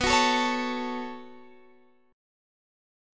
Listen to B7sus2 strummed